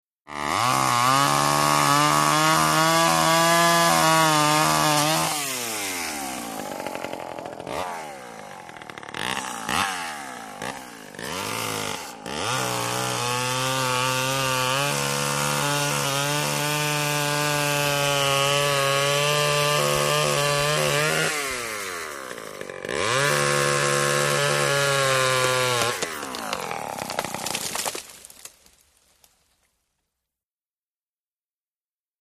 TreeCrackChainSaw PE699101
MACHINES - CONSTRUCTION & FACTORY CHAINSAW: EXT: Chain saw cutting down small tree, long cutting takes, tree crack at end, chain saw idle.